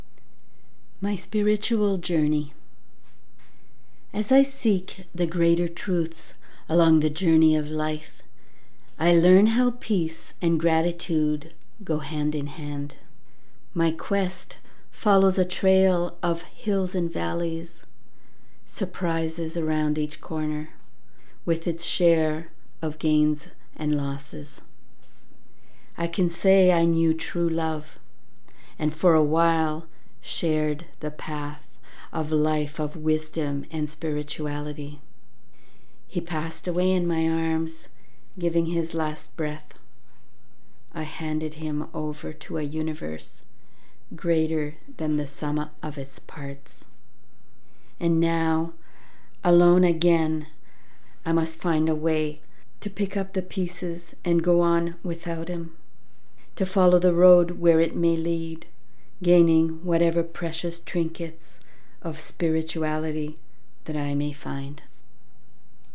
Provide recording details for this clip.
Read on air by invitation ~ March 27, 2021 'LATE NIGHT POETS'